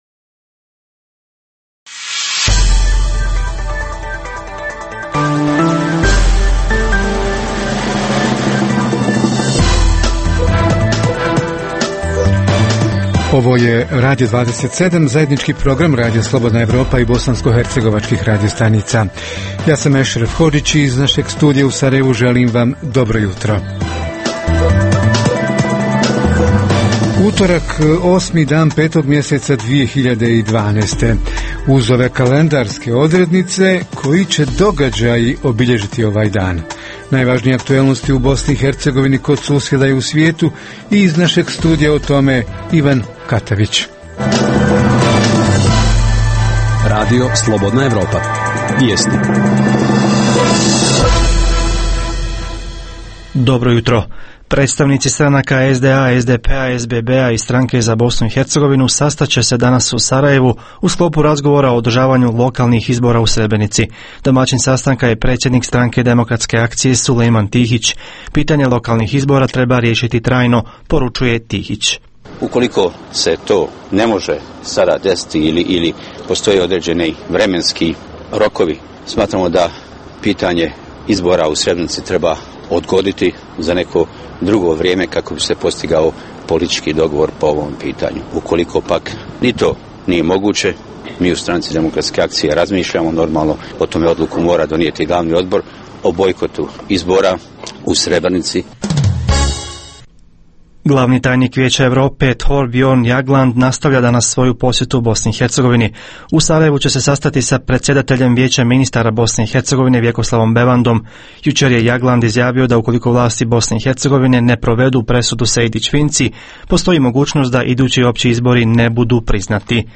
- Tema jutra: stanje na putevima nakon zime – udarne rupe i druga oštećenja – ko je odgovoran za održavanje i popravke, a ko za finansiranje održavanja, jesu li već počeli neki sanacioni radovi? - Reporteri iz cijele BiH javljaju o najaktuelnijim događajima u njihovim sredinama.
Redovni sadržaji jutarnjeg programa za BiH su i vijesti i muzika.